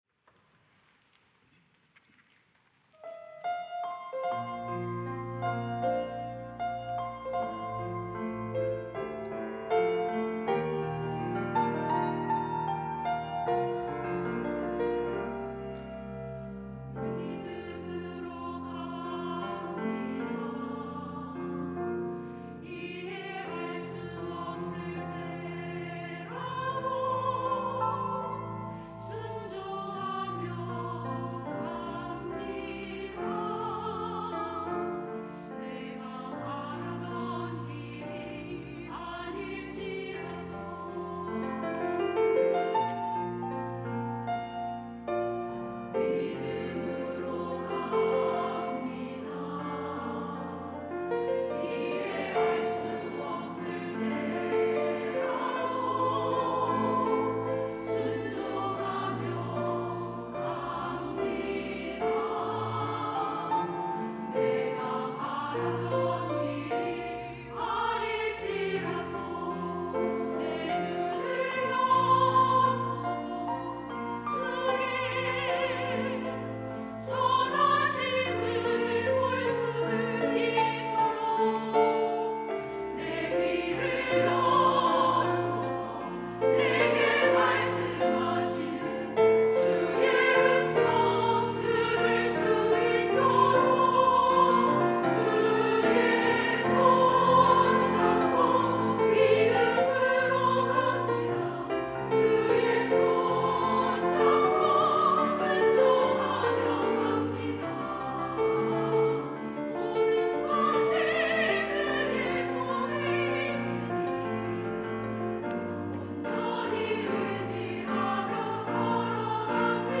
여전도회 - 믿음으로 갑니다
찬양대